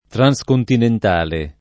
vai all'elenco alfabetico delle voci ingrandisci il carattere 100% rimpicciolisci il carattere stampa invia tramite posta elettronica codividi su Facebook transcontinentale [ tran S kontinent # le ] o trascontinentale agg.